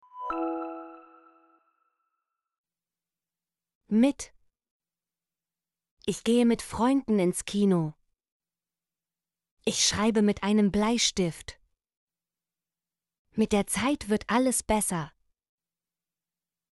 mit - Example Sentences & Pronunciation, German Frequency List